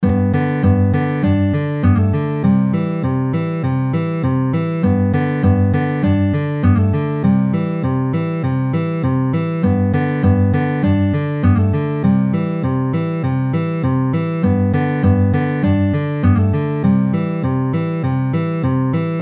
EXAMPLE 3 Verse